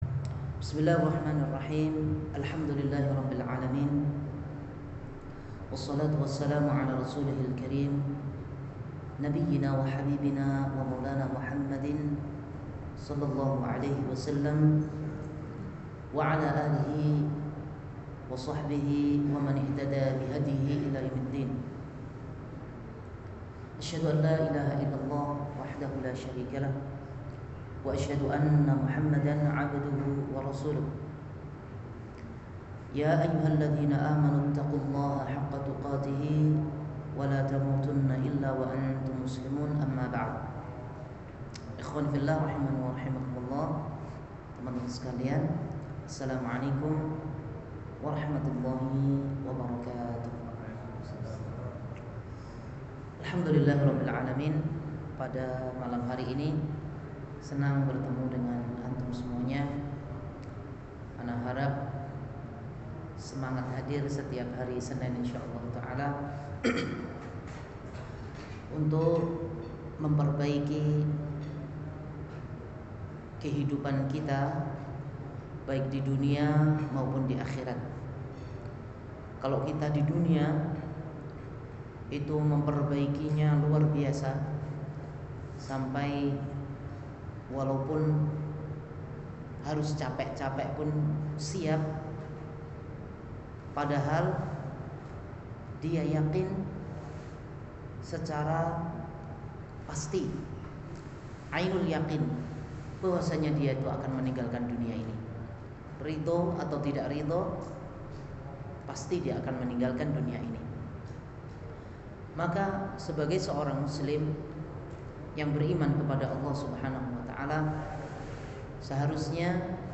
Kajian Keluarga – Wakra Wakra